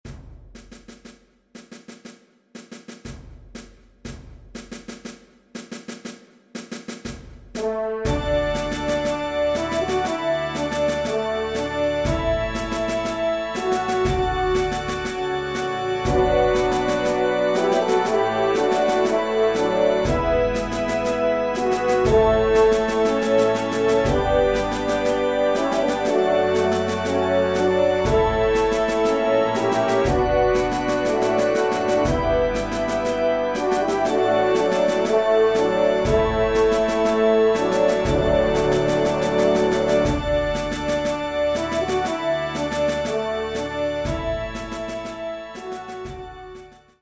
Musique populaire
ENSEMBLE